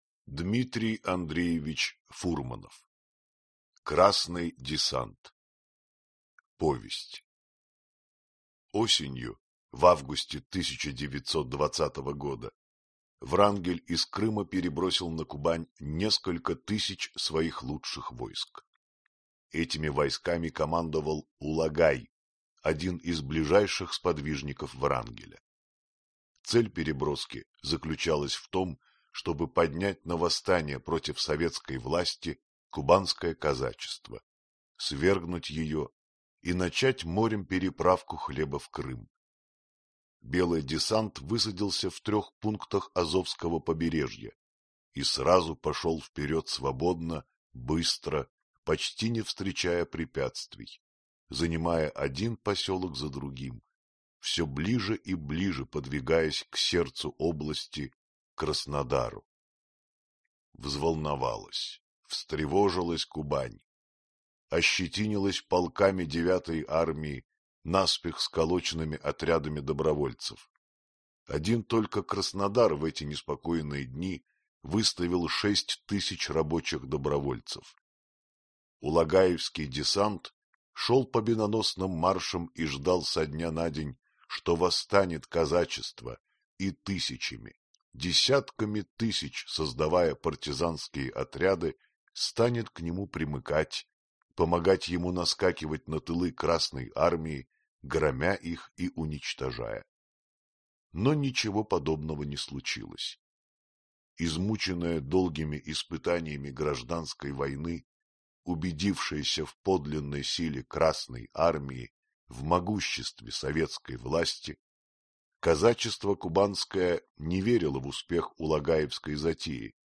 Аудиокнига Красный десант.